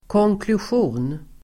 Ladda ner uttalet
Folkets service: konklusion konklusion substantiv, conclusion Uttal: [kångklusj'o:n] Böjningar: konklusionen, konklusioner Synonymer: resultat, sammanfattning, slutledning, slutsats Definition: slutsats